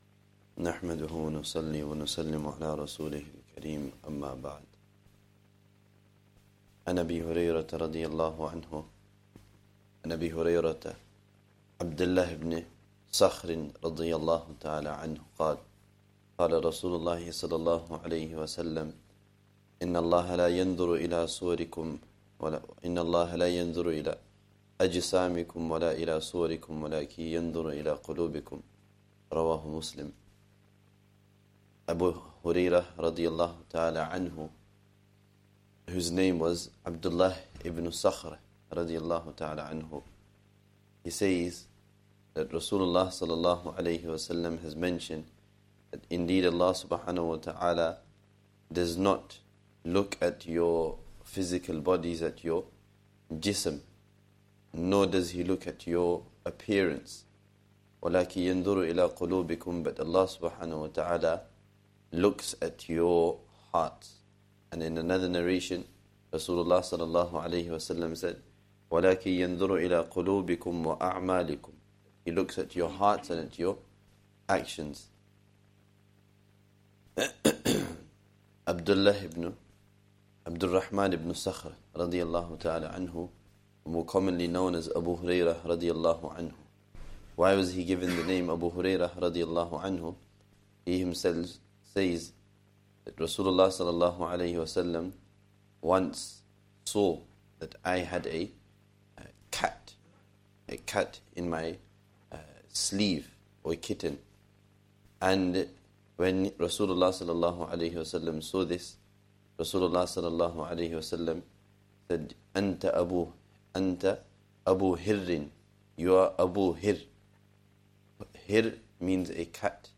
Lessons from Hadeeth